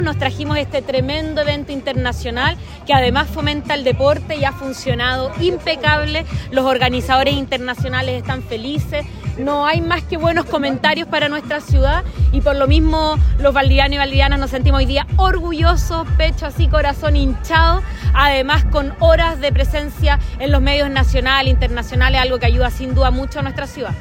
La mirada de los participantes fue compartida por la alcaldesa de Valdivia, Carla Amtmann, por el nivel de exposición que logró la ciudad a nivel nacional e internacional.